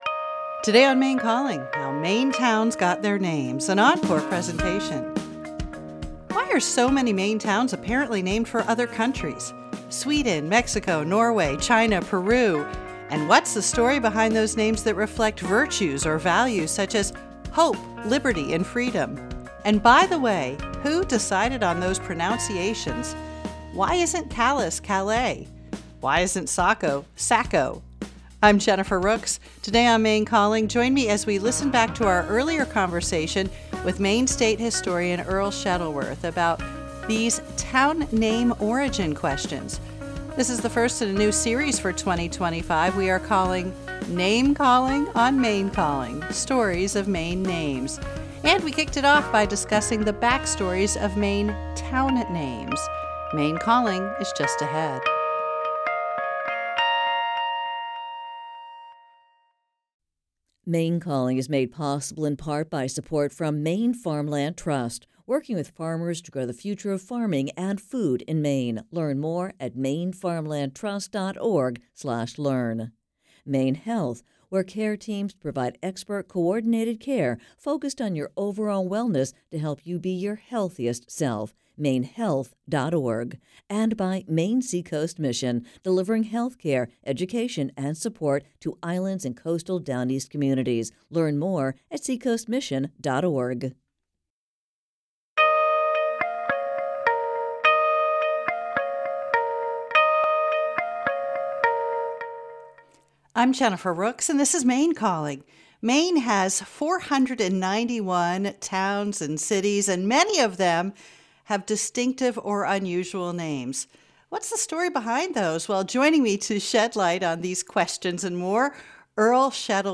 Maine Calling is a live, call-in radio program offering enlightening and engaging conversations on a wide range of topics.